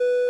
synth17l.wav